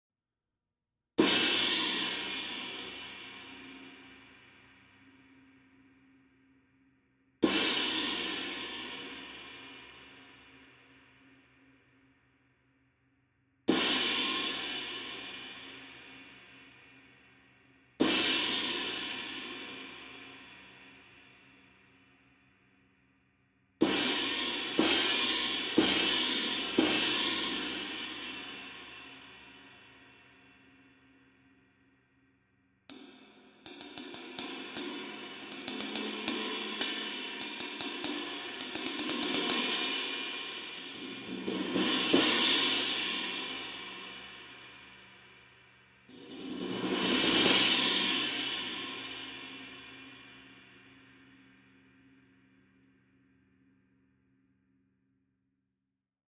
Heartbeat Custom Dry Cymbals: Perfect for lively rooms or small acoustic settings.
The difference is the lathing is reduced considerably from the Custom series to produce an extremely dry, quiet and very short resonance.
22″ Custom Dry Crash Cymbals: Approx 2000 Grams
22_Custom-Dry-Crash.mp3